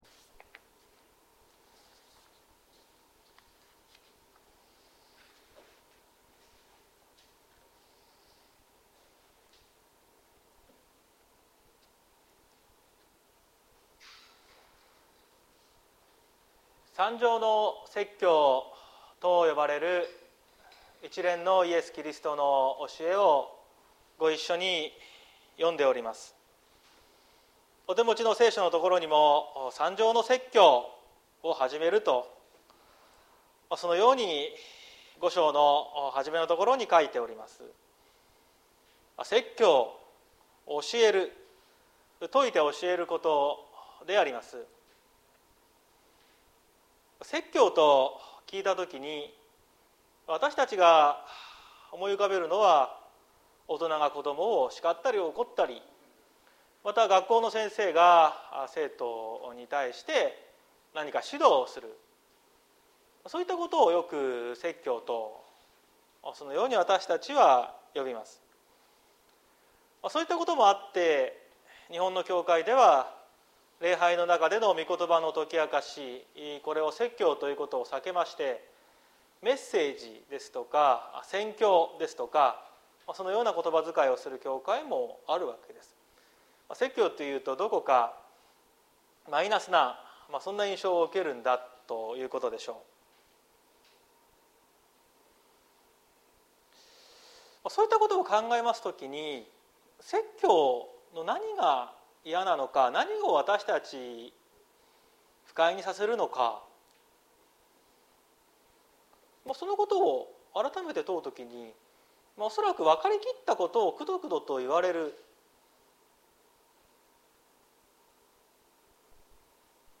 2022年09月11日朝の礼拝「味のある人生」綱島教会
説教アーカイブ。